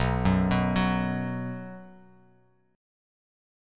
B5 Chord
Listen to B5 strummed